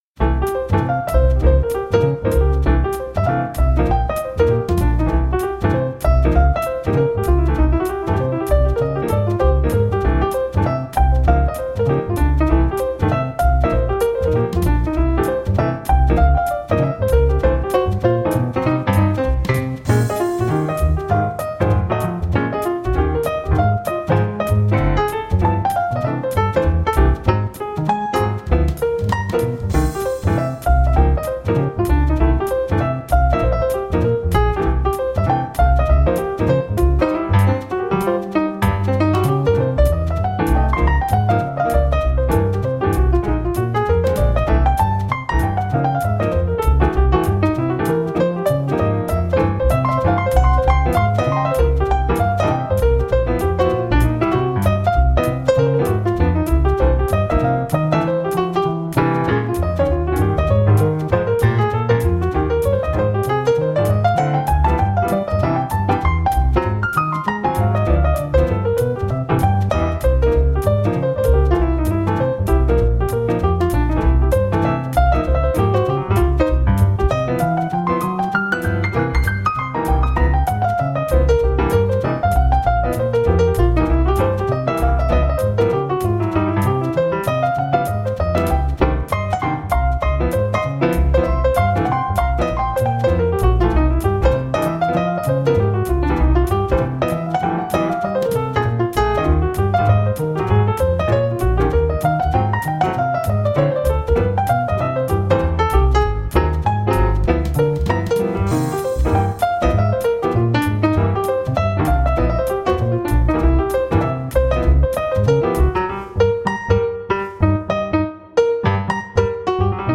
Toe teasin' jazz tunes for all occasions.